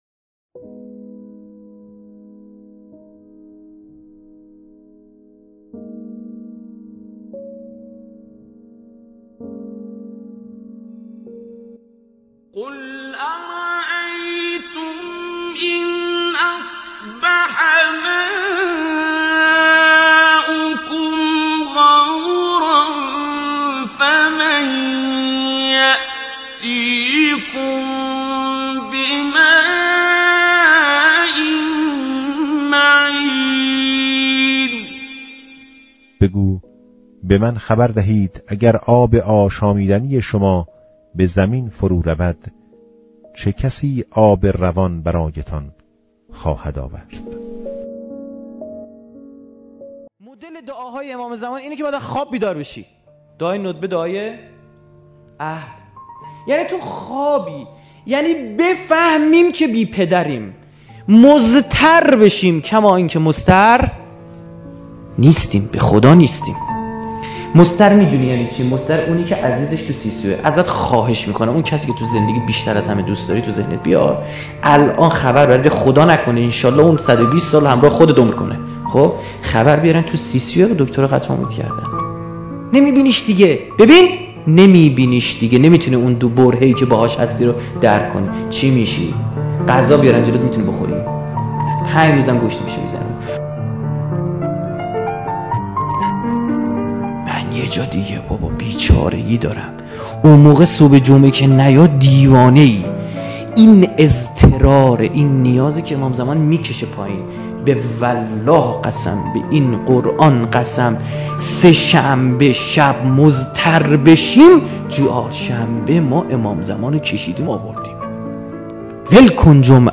صوت سخنرانی